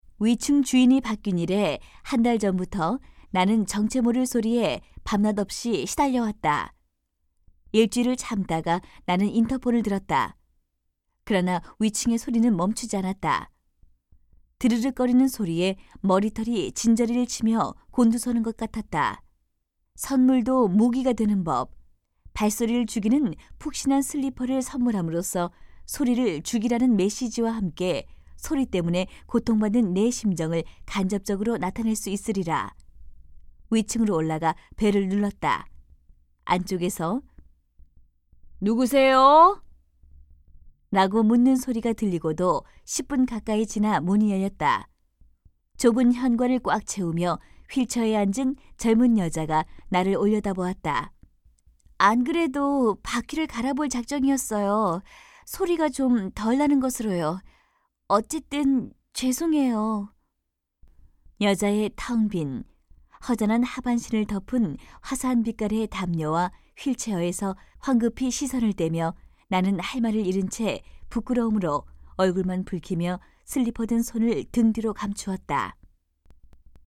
151쪽-내레이션.mp3